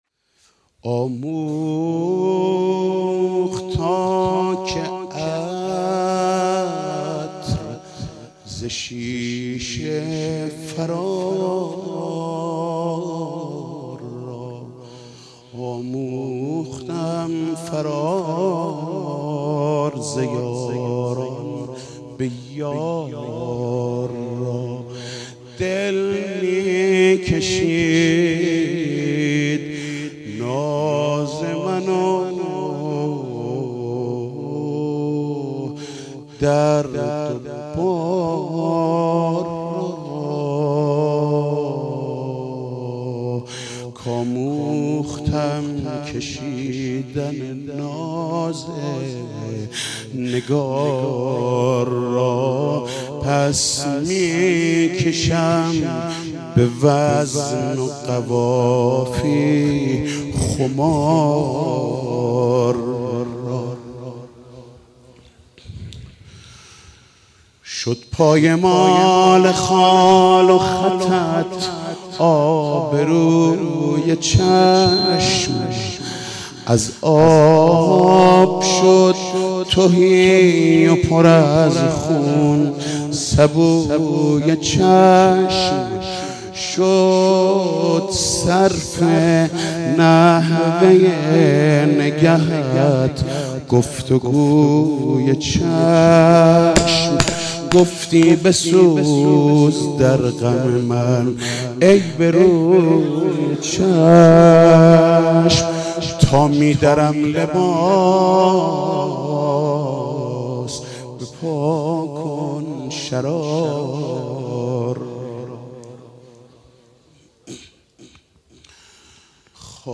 مدح: گر دم كنند خونِ دم ذوالفقار را